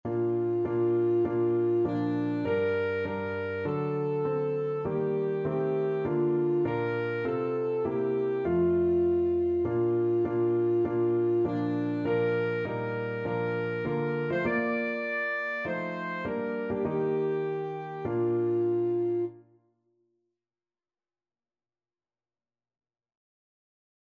Alto Saxophone
4/4 (View more 4/4 Music)
Bb major (Sounding Pitch) G major (Alto Saxophone in Eb) (View more Bb major Music for Saxophone )